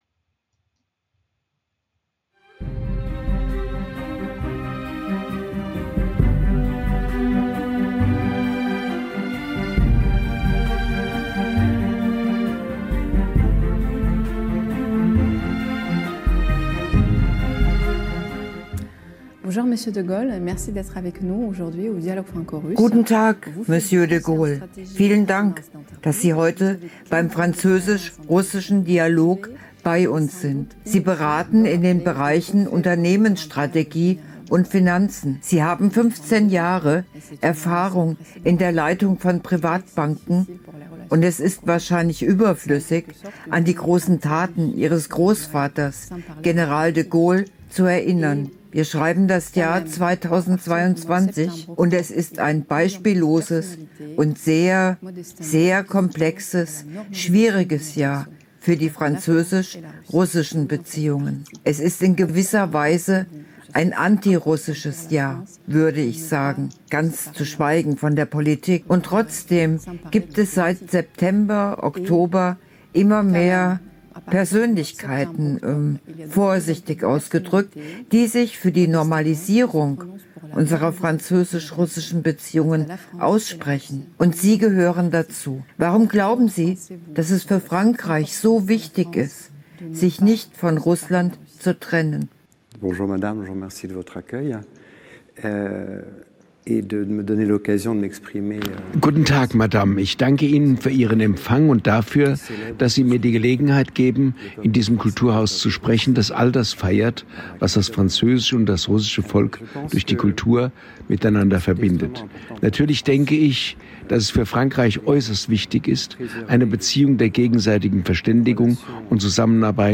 … mit deutscher Synchronisation.